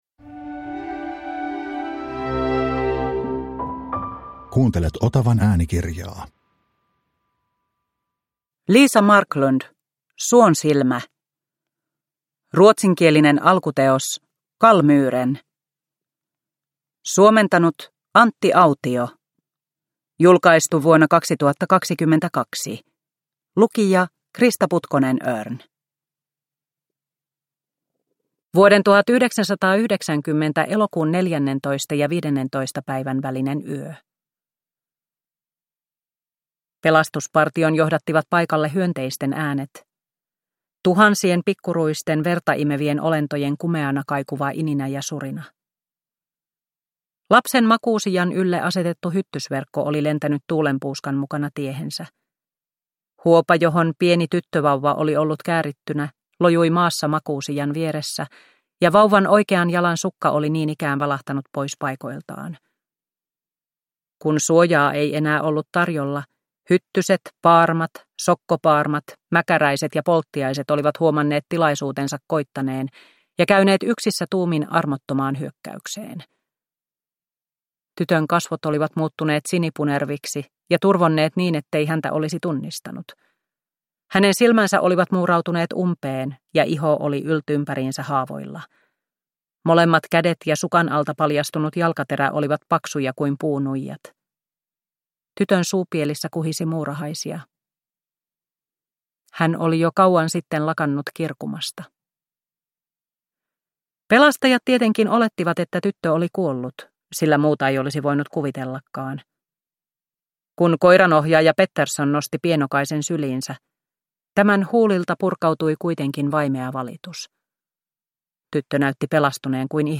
Suonsilmä – Ljudbok – Laddas ner